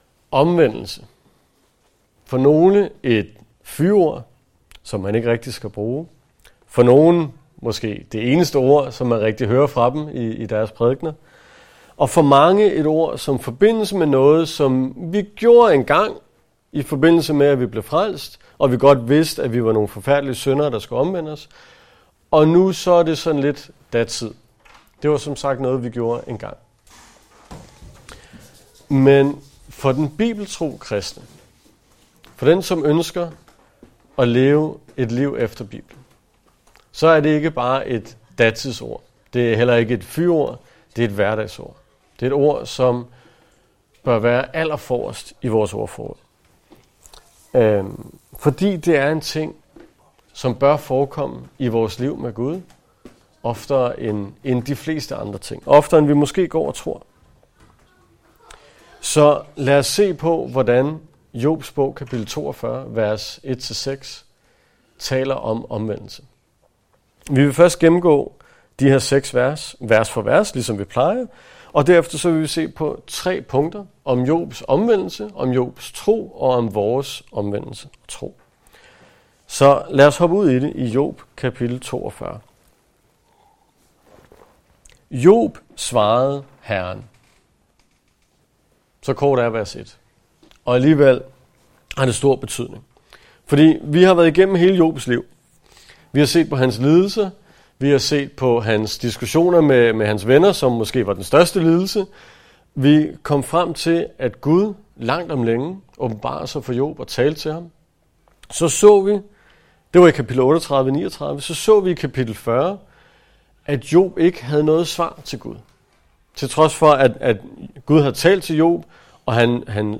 Bibelundervisning fra Gamle Testamente fra Jobs bog